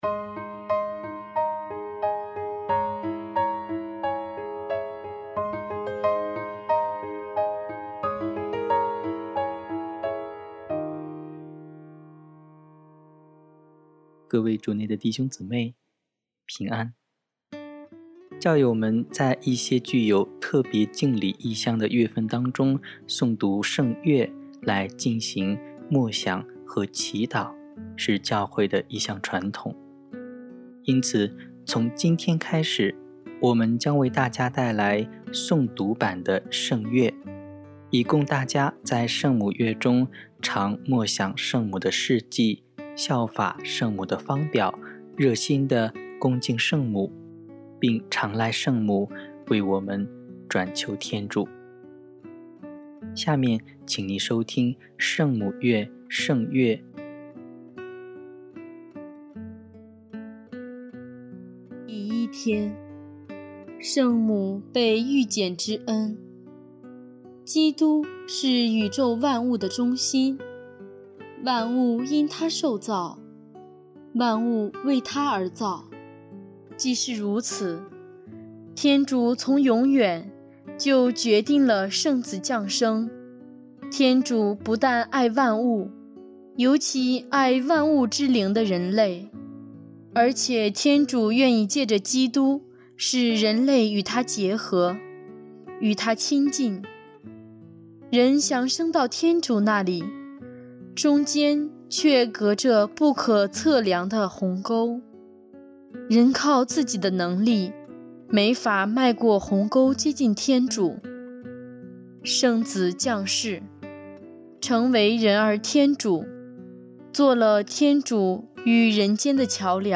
教友们在一些具有特别敬礼意向的月份当中诵读圣月，来进行默想和祈祷是教会的一些传统，因此，从今天开始，我们将带来诵读版的圣月，以供大家在圣母月中常默想圣母的事迹，效法圣母的芳表，热心的恭敬圣母，并常赖圣母为我们转求天主。